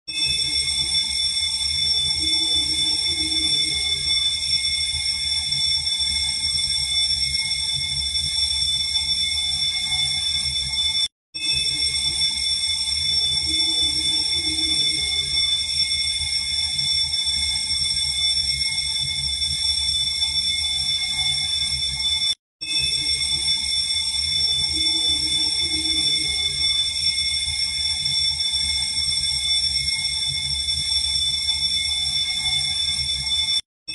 เสียงสัญญาณไฟไหม้คอนโด
เสียงกริ่งคอนโด เสียงเอฟเฟค ออนไลน์
หมวดหมู่: เสียงระฆัง เสียงนกหวีด
คำอธิบาย: นี่คือเสียงสัญญาณไฟไหม้คอนโด MP3 ซึ่งเป็นเสียงเตือนเมื่อมีเหตุเพลิงไหม้ มันใช้ในการแจ้งเตือนในกรณีฉุกเฉิน คุณสามารถนำเสียงนี้มาใช้ในวิดีโอที่ไม่มีลิขสิทธิ์ได้โดยสบาย ๆ เช่นกัน
tieng-chuong-bao-chay-chung-cu-www_tiengdong_com.mp3